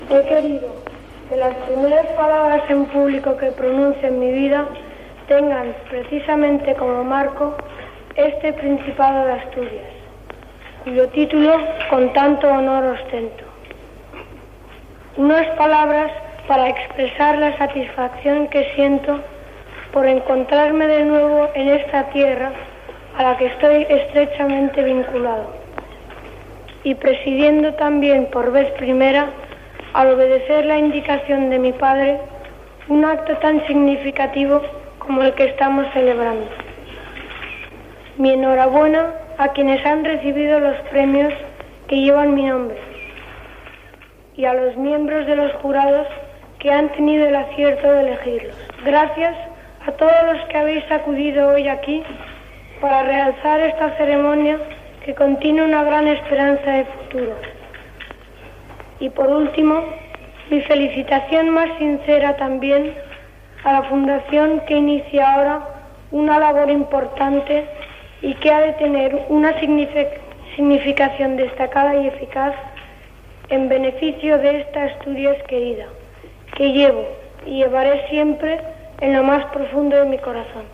Primer discurs públic del Prícipe de Asturias Felipe de Borbón, amb 13 anys, al final de l'acte de lliurament dels Premios Prícipe de Asturias al teatre Campoamor d'Oviedo
Informatiu